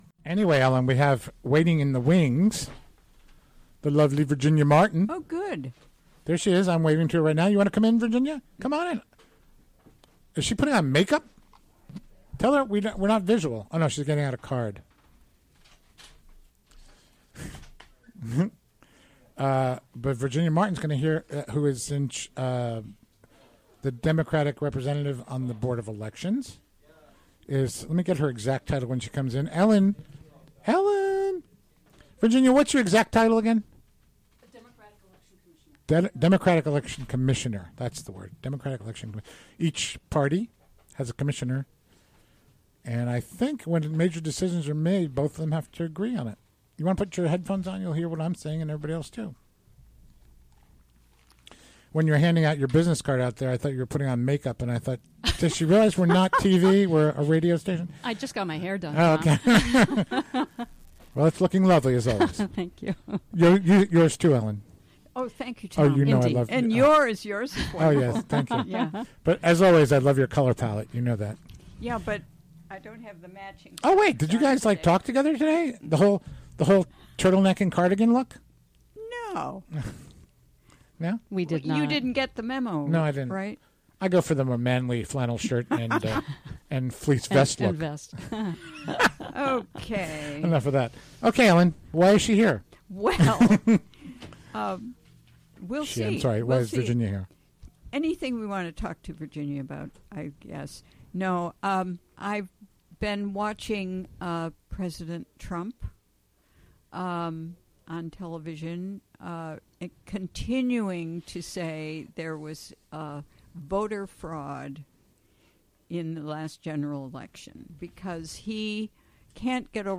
Recorded during the WGXC Afternoon Show Thursday, January 26, 2017.